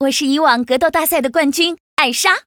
文件 文件历史 文件用途 全域文件用途 Choboong_tk_01.ogg （Ogg Vorbis声音文件，长度0.0秒，0 bps，文件大小：31 KB） 源地址:游戏语音 文件历史 点击某个日期/时间查看对应时刻的文件。